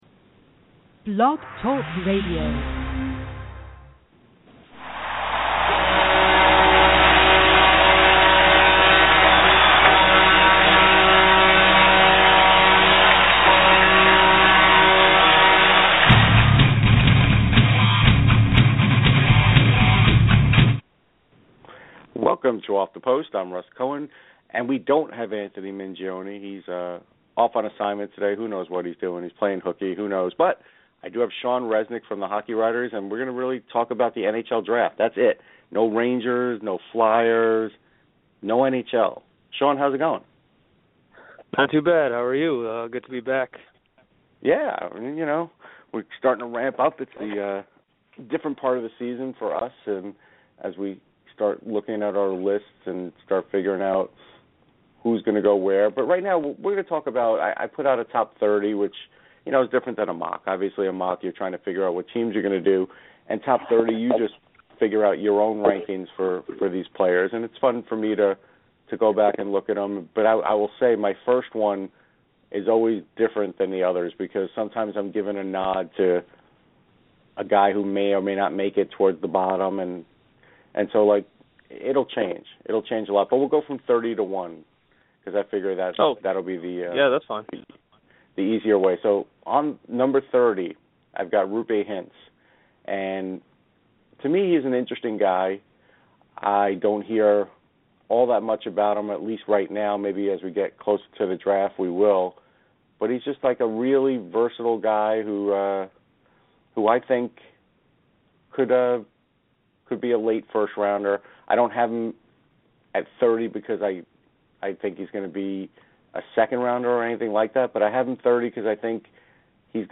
Sorry we got cut off.